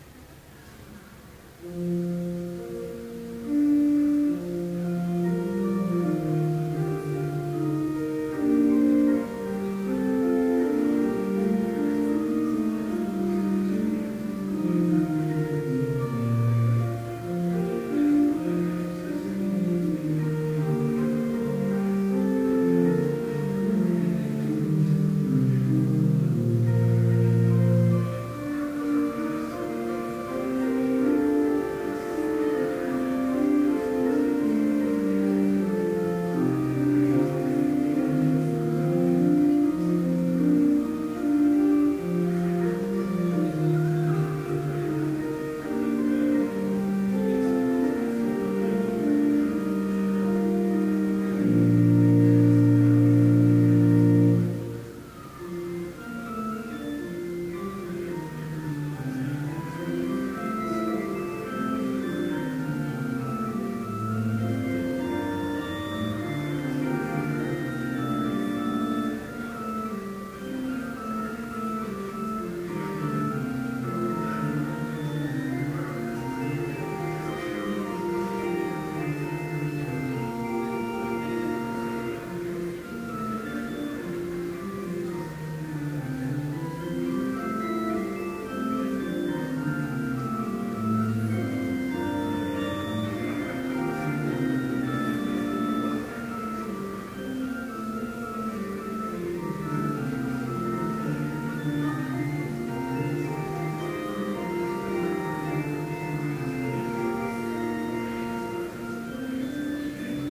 Complete Service
This Chapel Service was held in Trinity Chapel at Bethany Lutheran College on Monday, November 26, 2012, at 10 a.m. Page and hymn numbers are from the Evangelical Lutheran Hymnary.